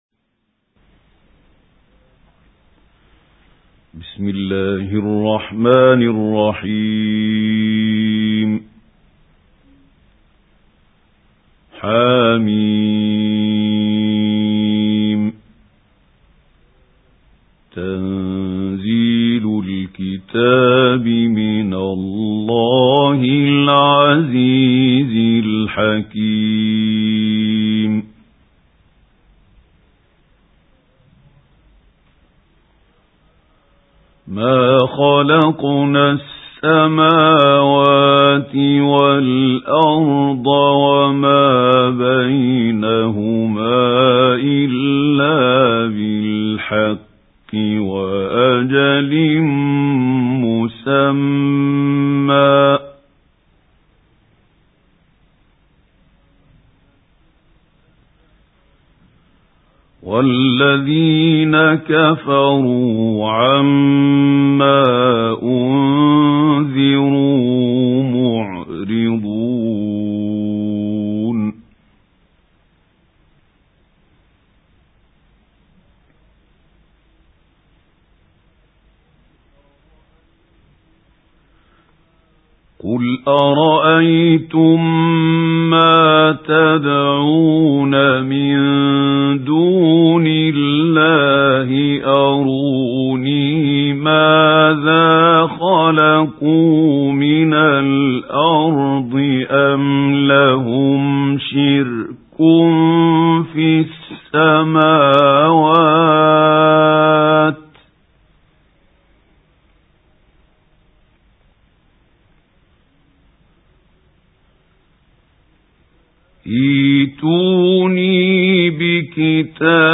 سُورَةُ الأَحۡقَافِ بصوت الشيخ محمود خليل الحصري